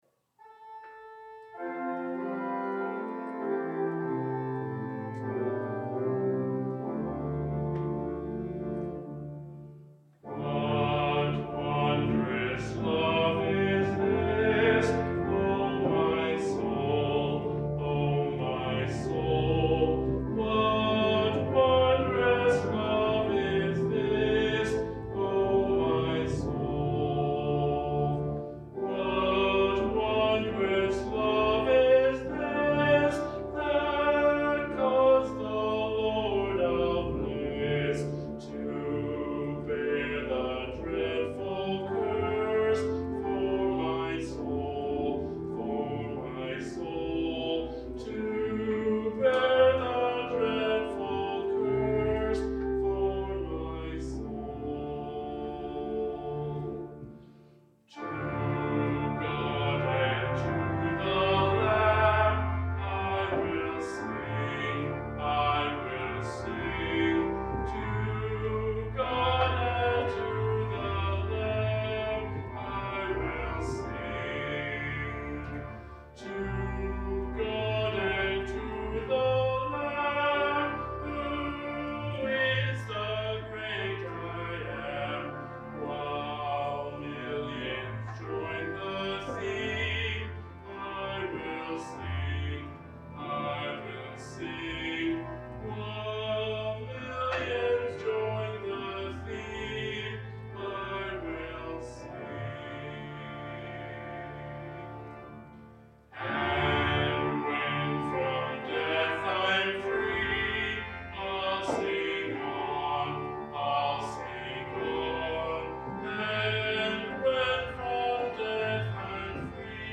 Sermons | The Church of the Good Shepherd
Please note that, due to a technical issue, the sound quality has more of an echo in it than you usually hear in our sermon videos.